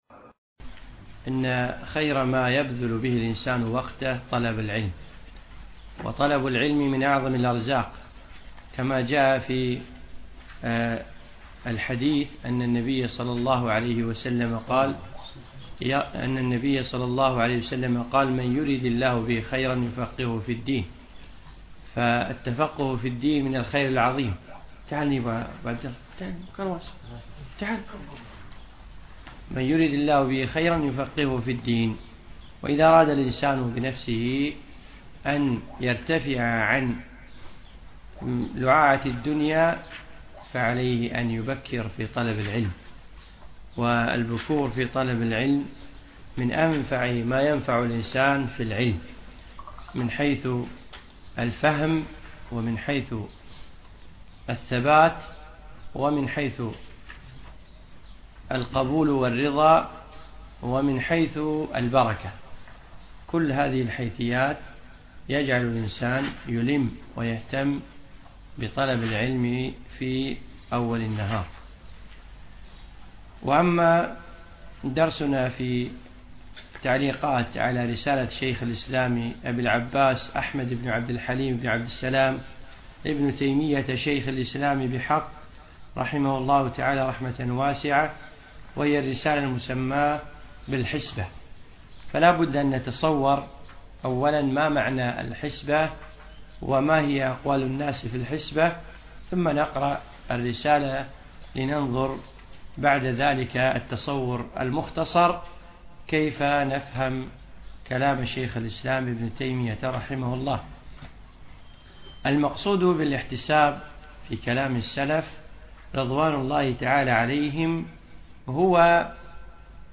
من دروس الشيخ في دولة الإمارات